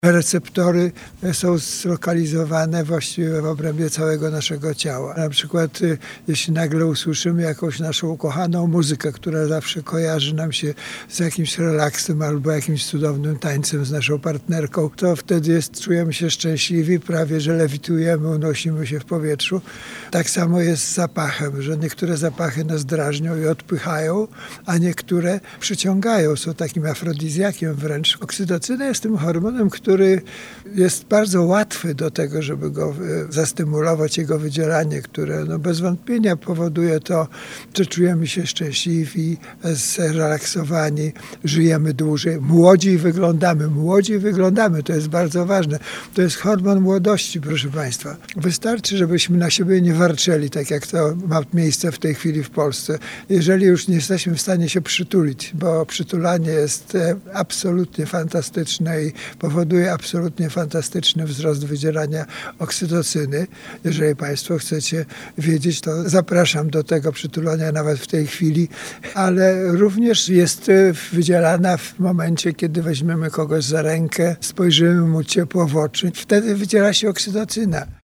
Cała rozmowa w piątek 14 lutego po godz. 8:10 w audycji „Poranny Gość”.